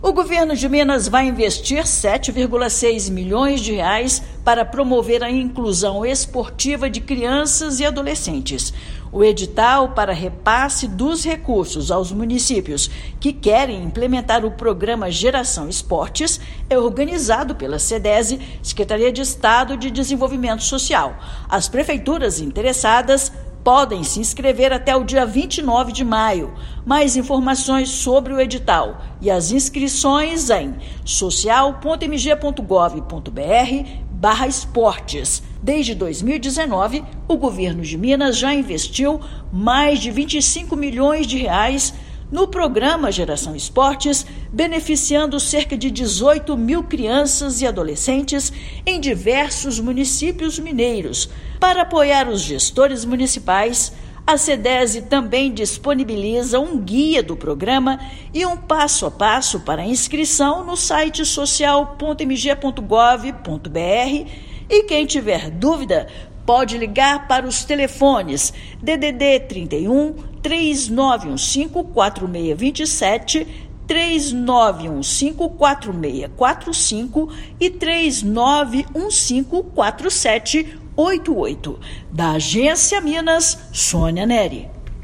Programa Geração Esporte oferece iniciação esportiva no contraturno escolar para estudantes de 6 a 17 anos. Ouça matéria de rádio.